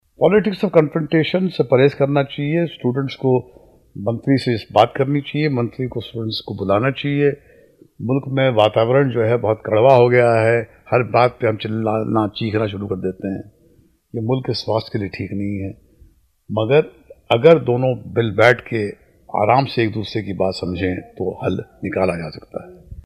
ऍफ़.टी.आई.आई के छात्रों के विद्रोह पर क्या है महेश भट्ट की राय. सुनिए बीबीसी से उनकी ख़ास बातचीत में.